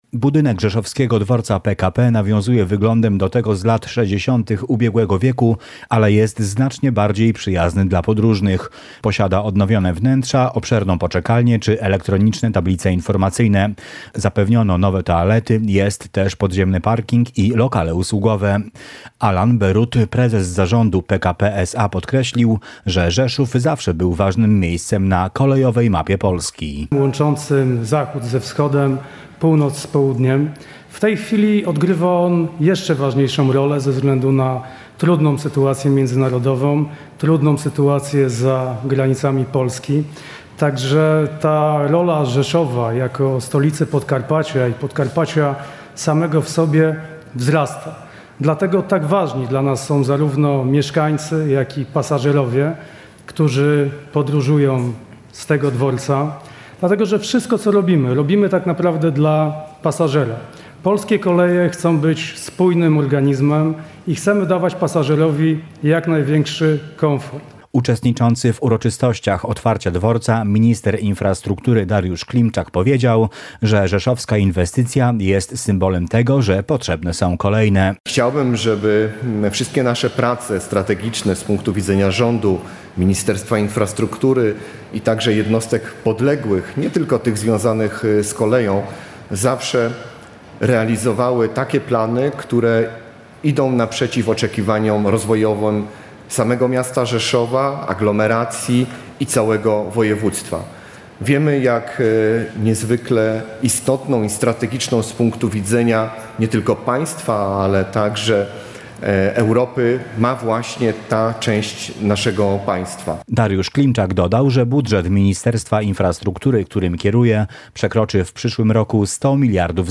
Minister infrastruktury Dariusz Klimczak powiedział, że rzeszowska inwestycja jest symbolem tego, że potrzebne są kolejne.
Relacja